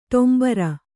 ♪ ṭombara